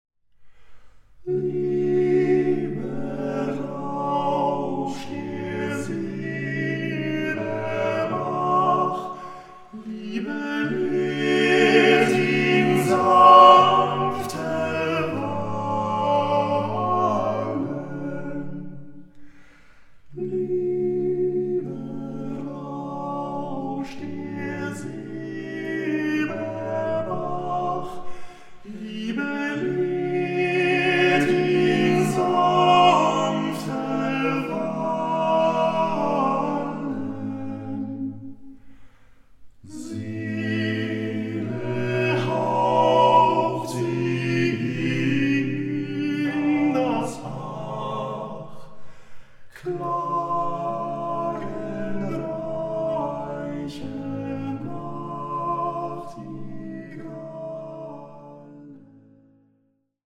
the eight singers revive an entire folk culture!